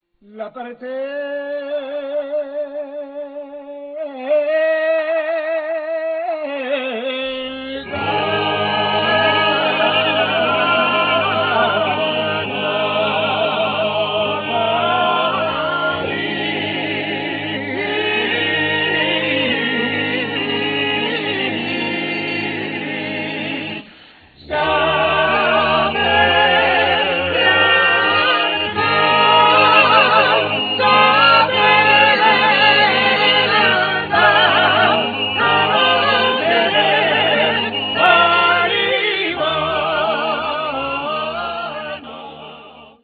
Trallalero, Polyphonie Génoies